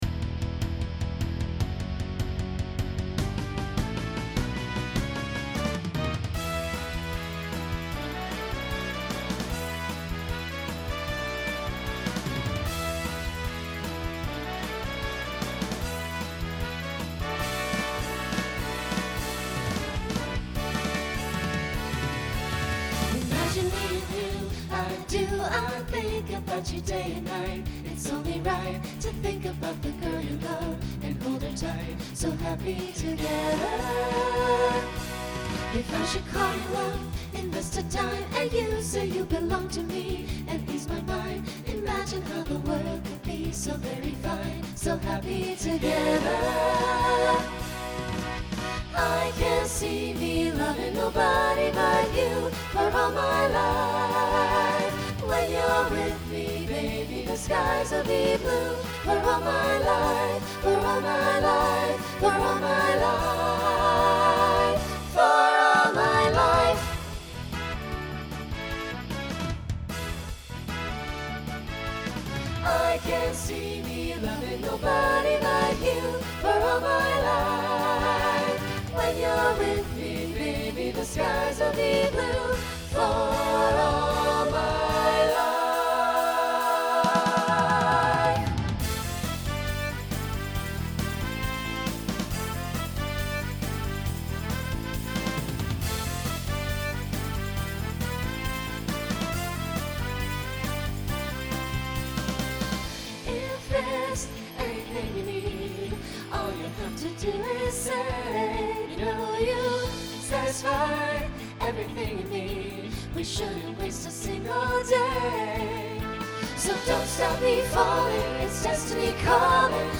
Genre Pop/Dance , Rock Instrumental combo
Opener Voicing SATB